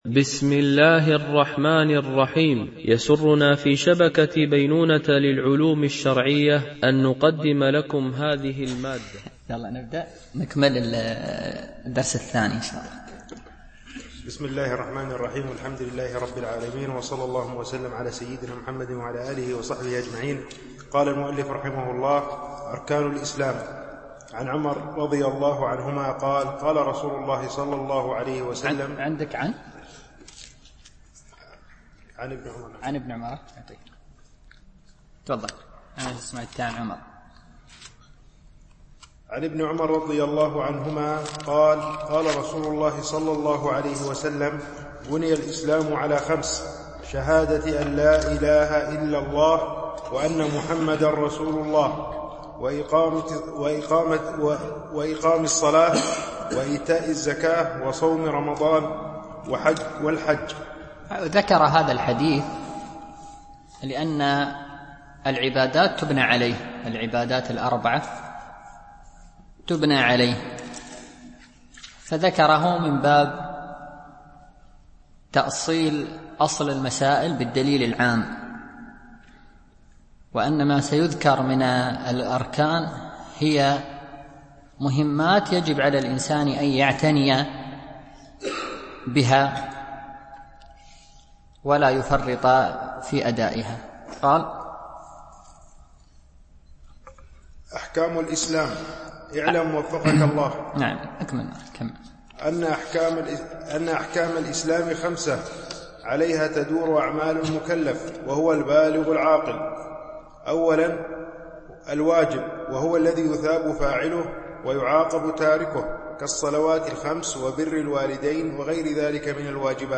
شرح الفقه المالكي ( تدريب السالك إلى أقرب المسالك) - الدرس 2 (كتاب الطهارة)